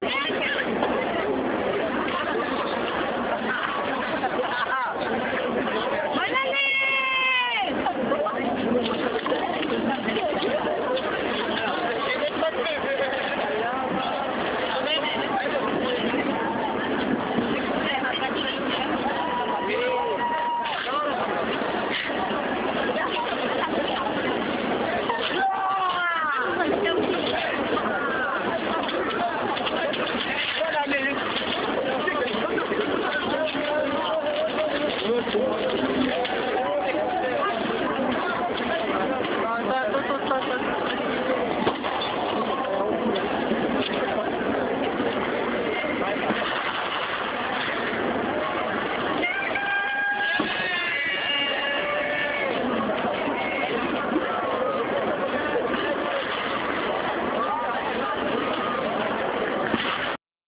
12時になる5分前、新年になるのを待てない人が「おめでとう。（Bonne Année ： ボナネ）」と叫ぶとあちらこちらで、新年を祝う声が聞こえる。
シャンゼリゼの騒ぎ